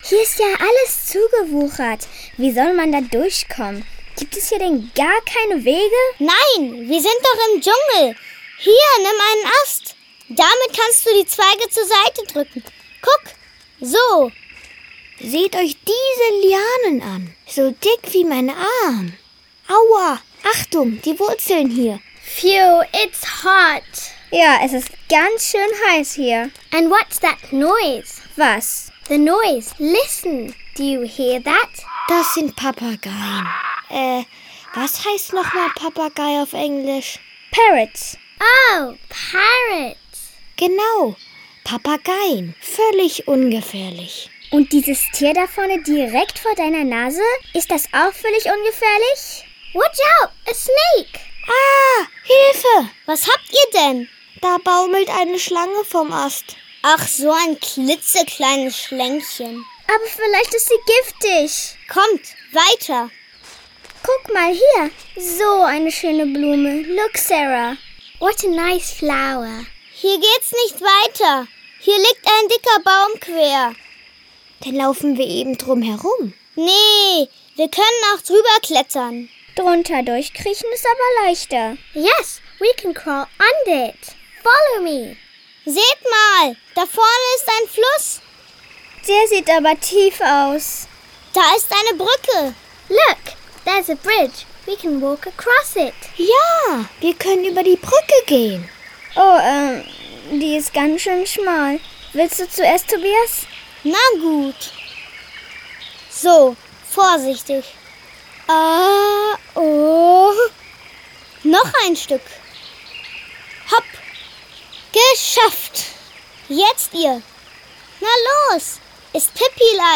Hörspiel-CD, die auch alleine verwendet werden kann